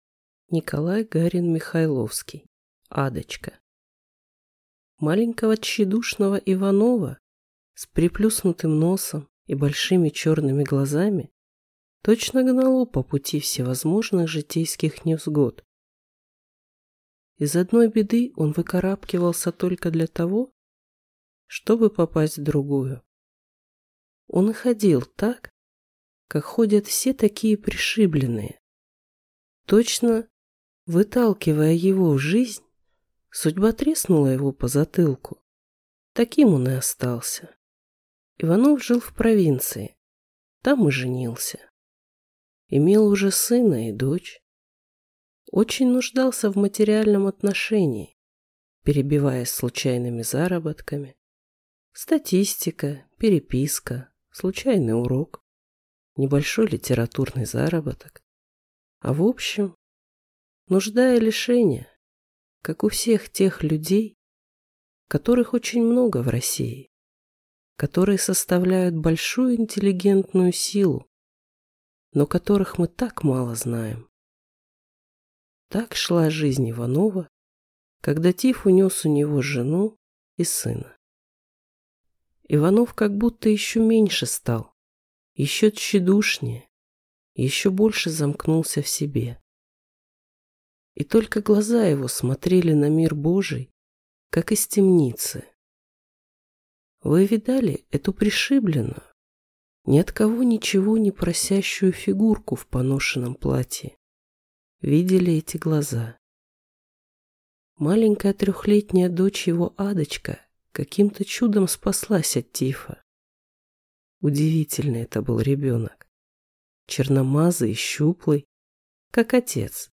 Аудиокнига Адочка | Библиотека аудиокниг